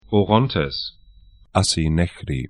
Orontes o'rɔntɛs Asi nehri 'asi 'nɛçri tr Fluss / stream 36°02'N, 35°58'E